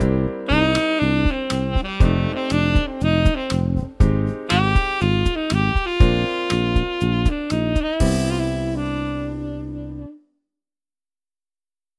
Take a look at the short melody and progression I wrote below.